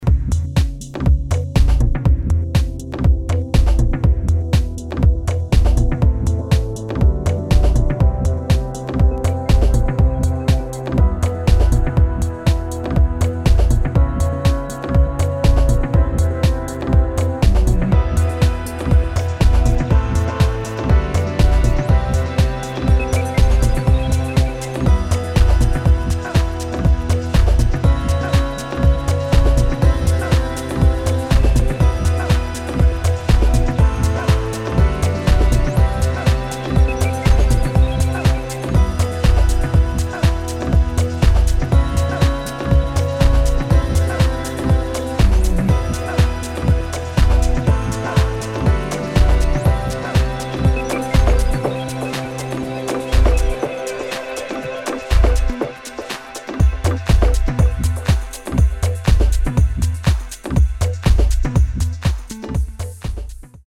[ DEEP HOUSE | NU-DISCO ]